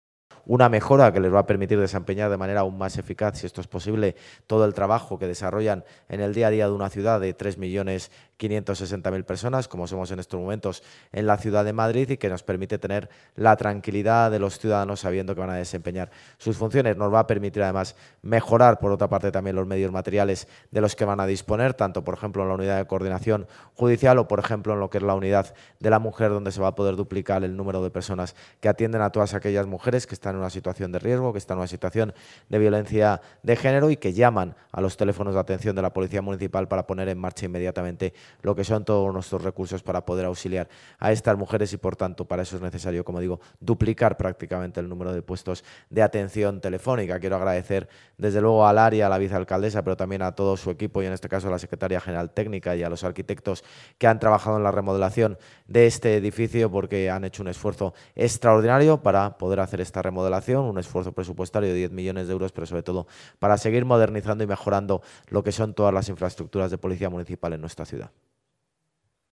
Nueva ventana:Intervención del alcalde de Madrid, José Luis Martínez-Almeida, durante la visita a las instalaciones de la nueva comisaría